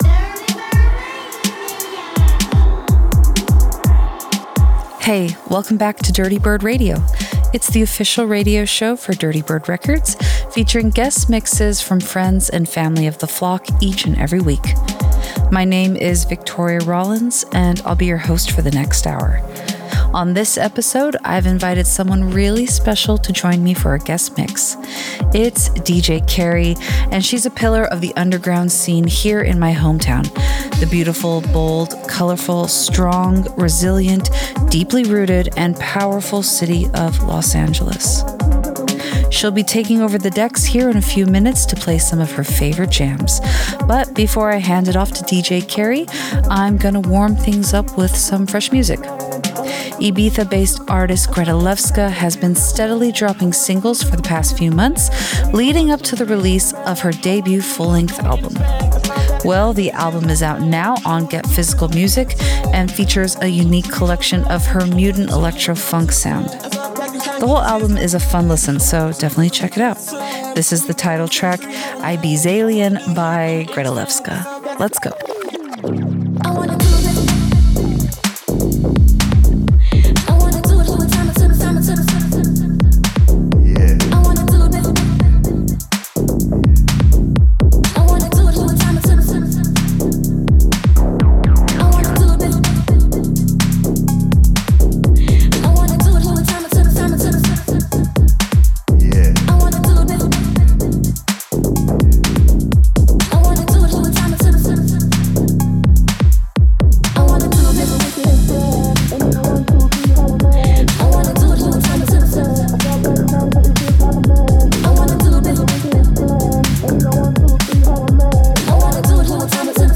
a deep, leftfield set